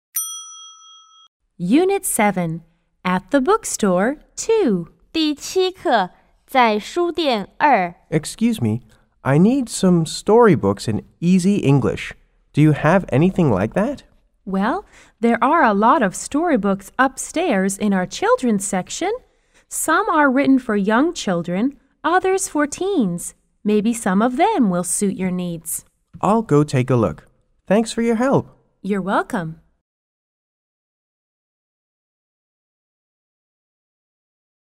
S= Student C= Clerk